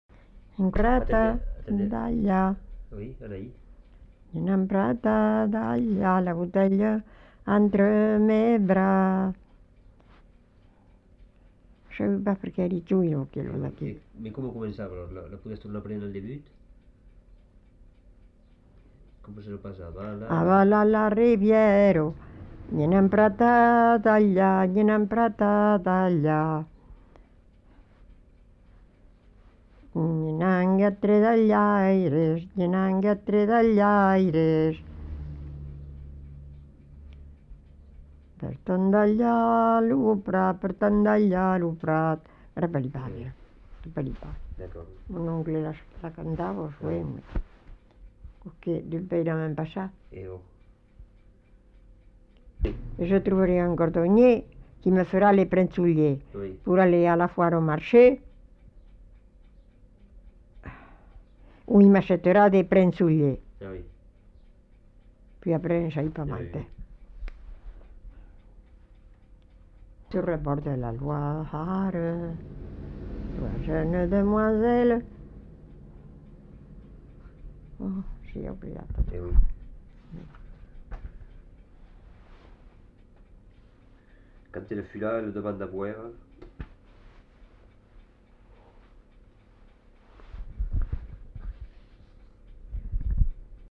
Aire culturelle : Haut-Agenais
Lieu : Fumel
Genre : chant
Effectif : 1
Type de voix : voix de femme
Production du son : chanté
Notes consultables : Bribes d'un chant en fin de séquence